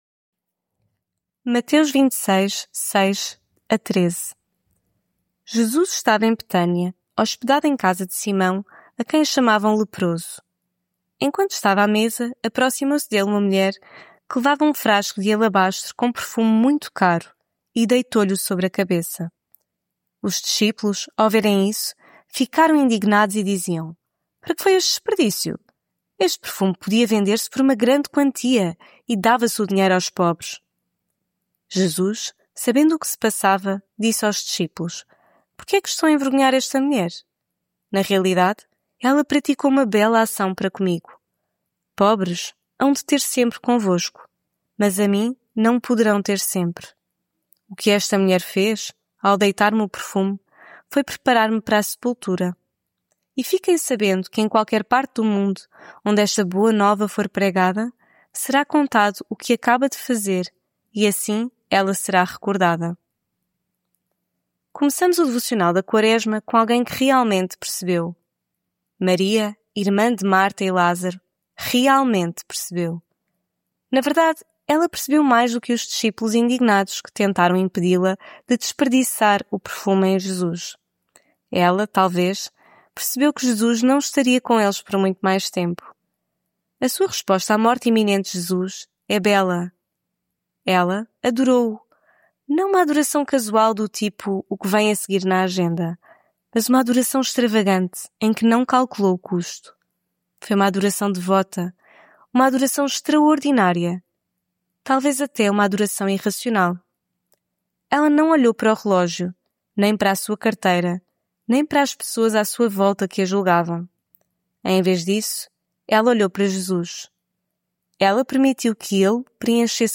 Devocional Quaresma